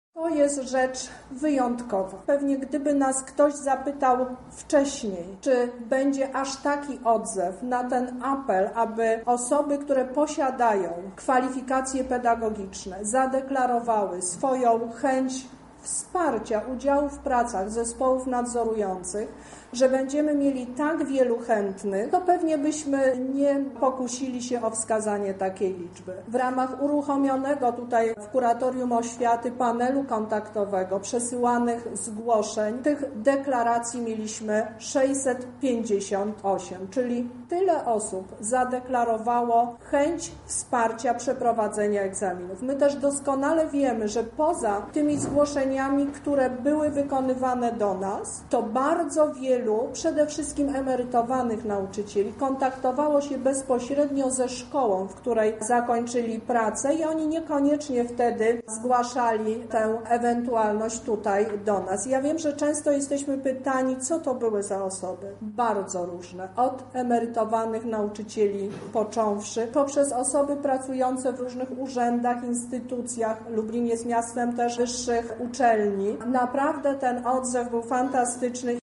Lubelska Kurator Oświaty Teresa Misiuk podkreśla, że udział alternatywnych członków był bardzo ważny: